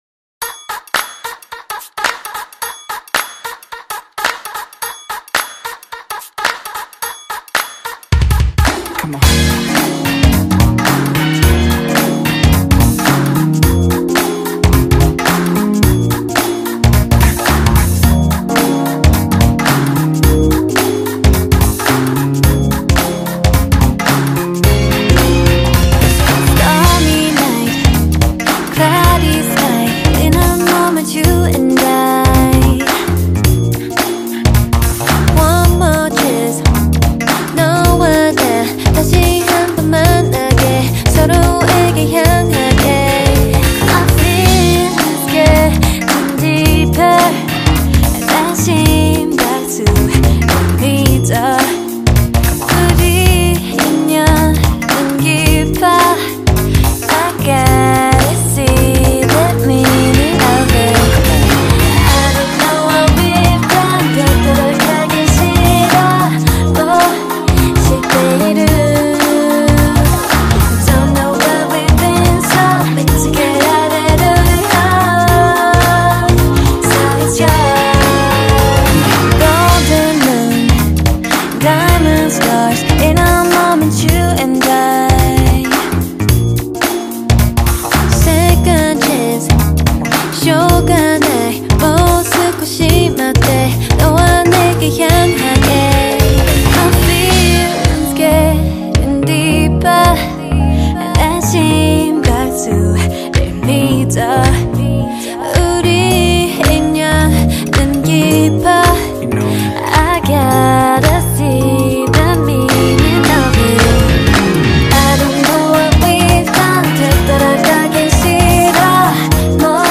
Женская К-поп группа